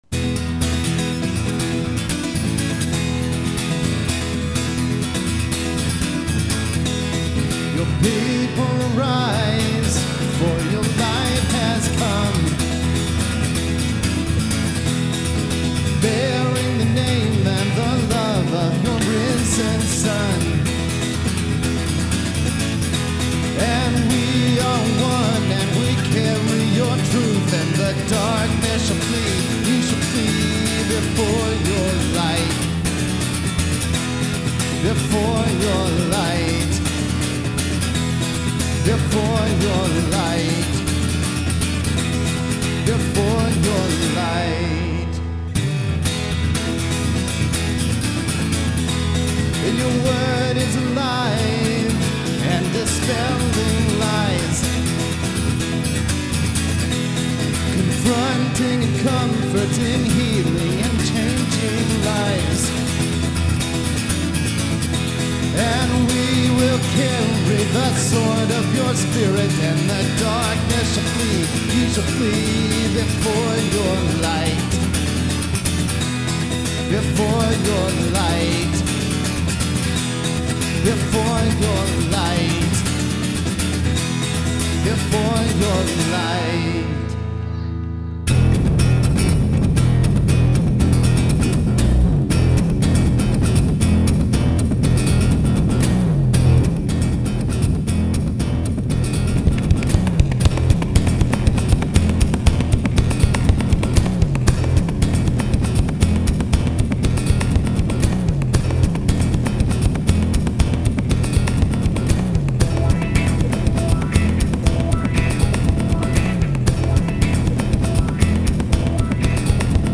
1. I step on the octave pedal and play the bass line.